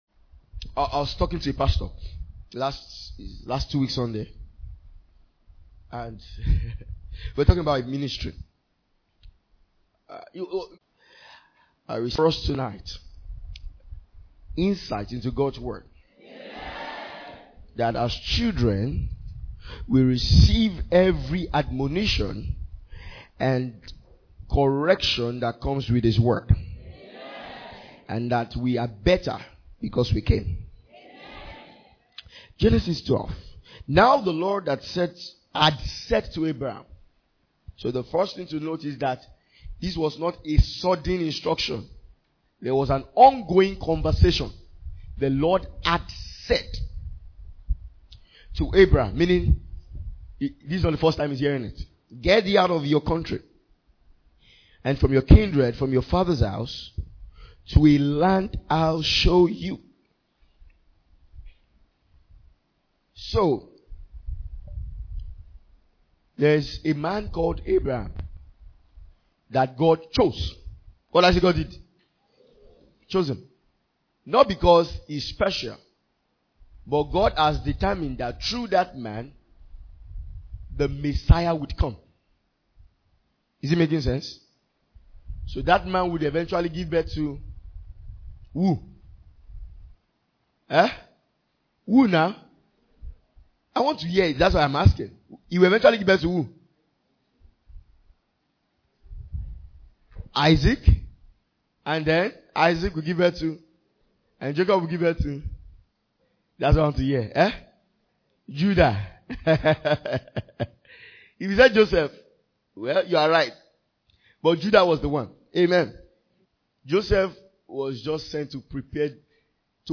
Message from our annaul Ministers’ Retreat 2025